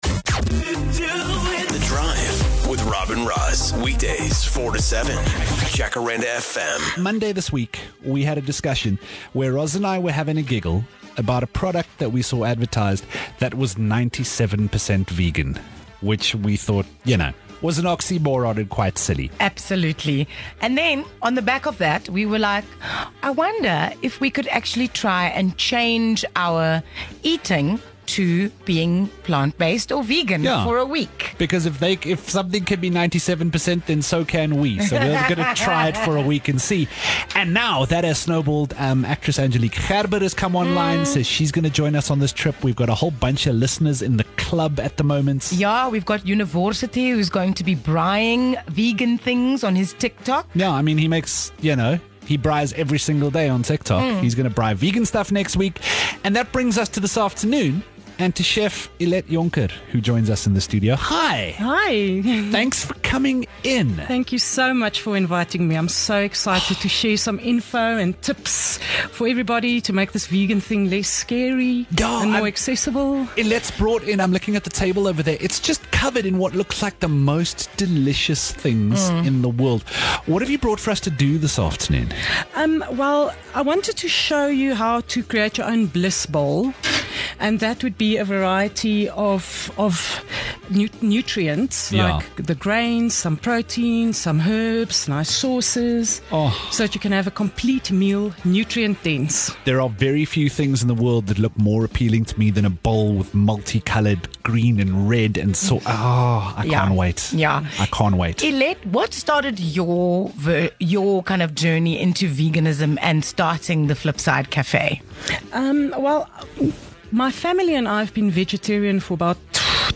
Take a listen as they chat all things vegan.